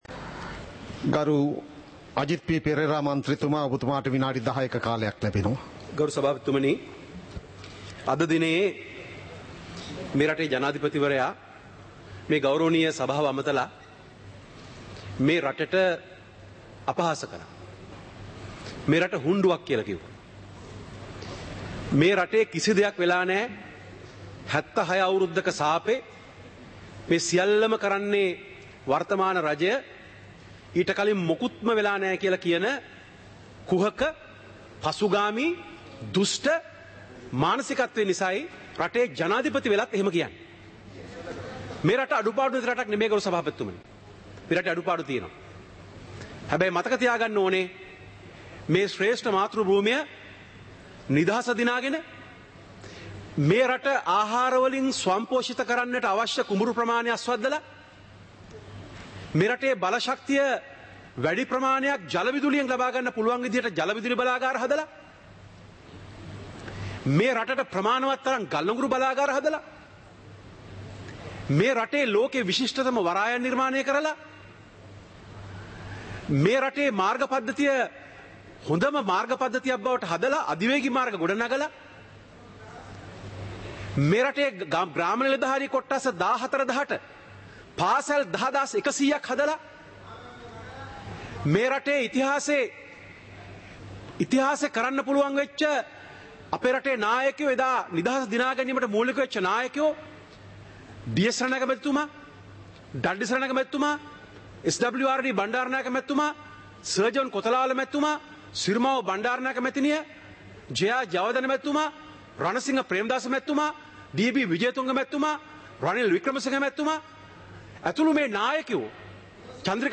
சபை நடவடிக்கைமுறை (2026-03-03)
நேரலை - பதிவுருத்தப்பட்ட